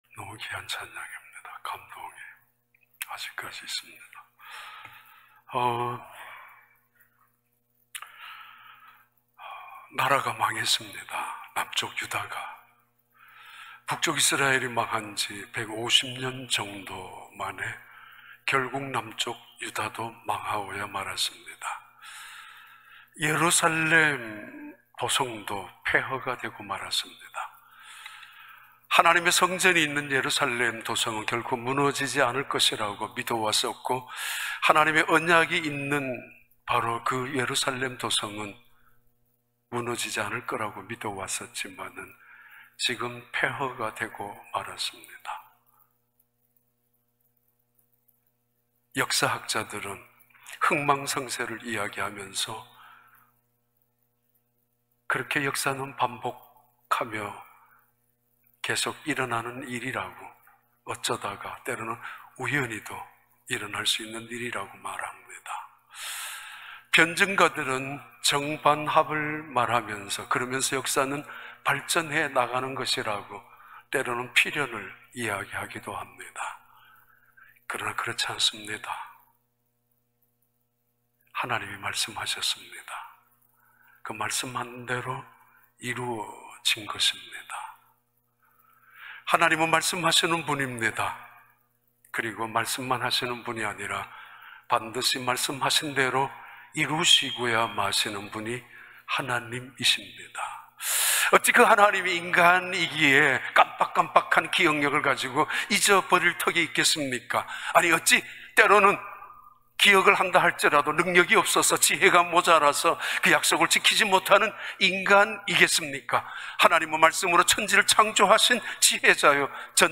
2021년 4월 18일 주일 4부 예배